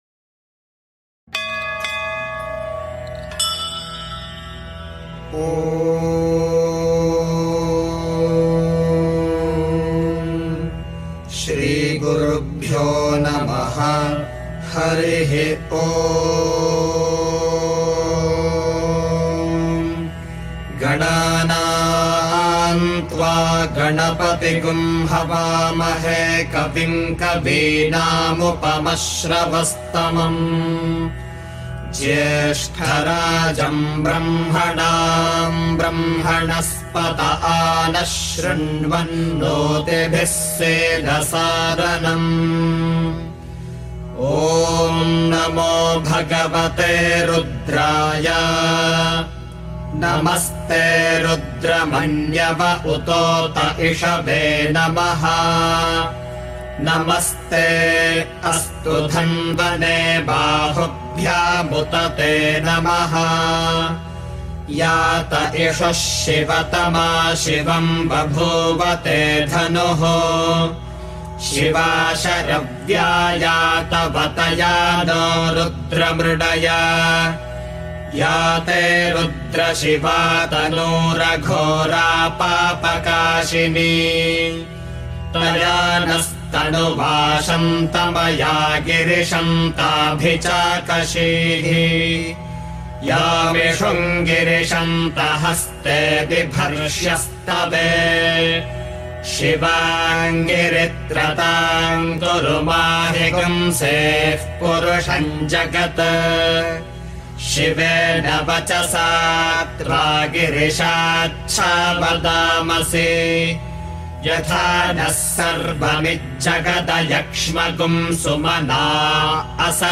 You can derive great benefit just by listening to the recited text of the Shri Rudram.